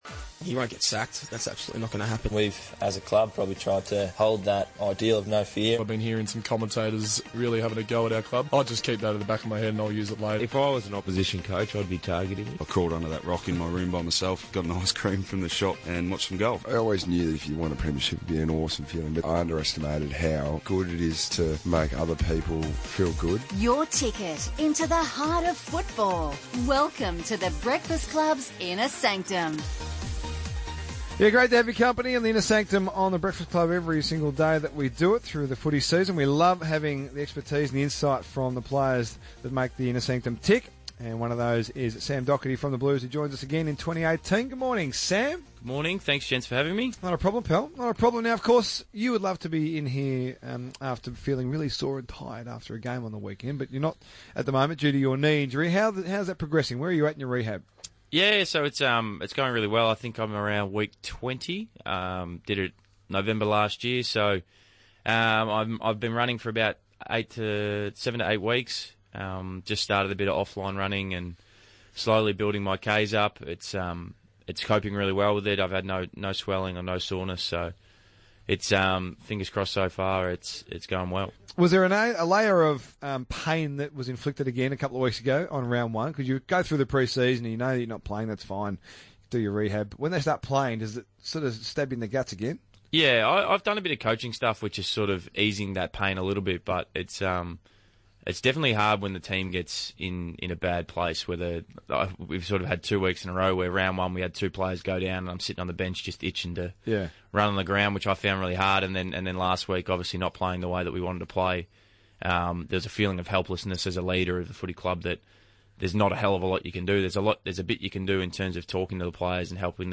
Carlton vice-captain Sam Docherty joins the RSN Breakfast Club ahead of the Blues' Round 3 clash with Collingwood.